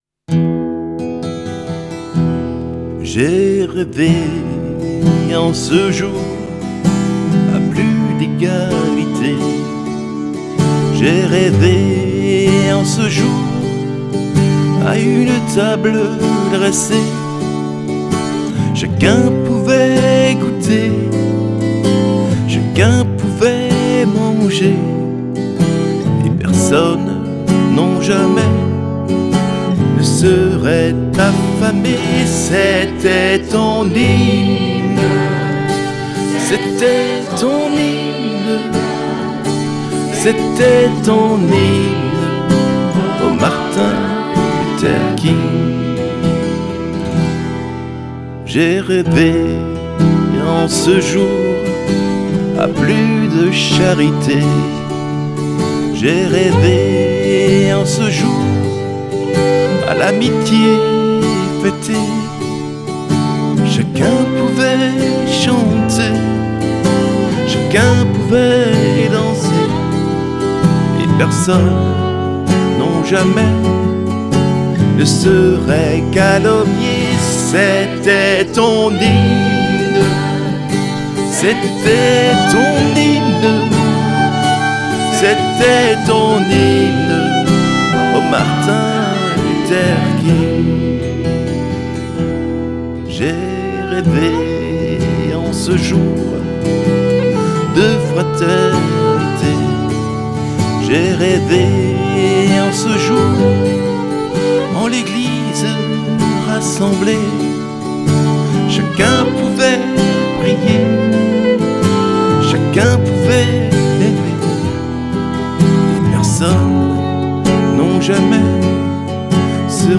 Guitares
Violon